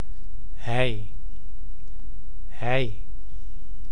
Ääntäminen
Synonyymit 'ie Ääntäminen : IPA: [hɛj] Tuntematon aksentti: IPA: /ɦɛi̯/ Haettu sana löytyi näillä lähdekielillä: hollanti Käännös Ääninäyte 1. il {m} France Esimerkit Hij loopt. — He walks. hij heet Bernard Il s'appelle Bernard Luokat Germaanisista kantakielistä johdetut sanat Persoonapronominit Pronominit